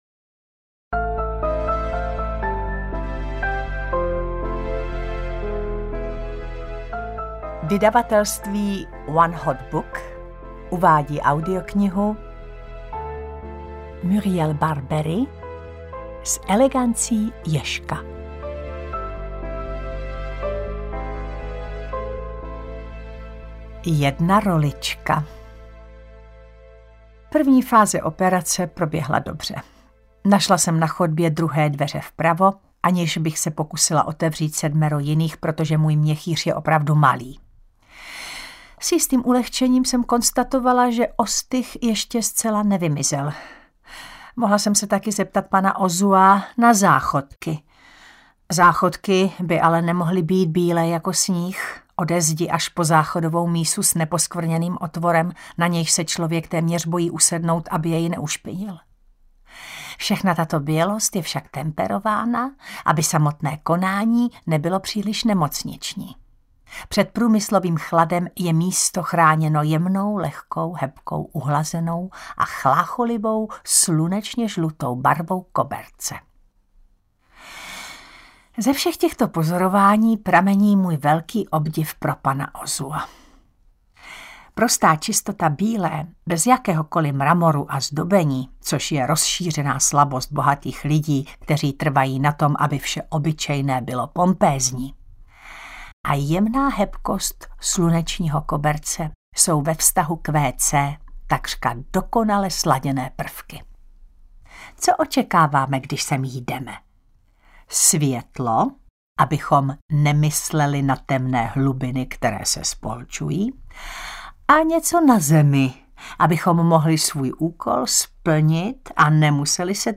S elegancí ježka audiokniha
Ukázka z knihy
• InterpretTaťjana Medvecká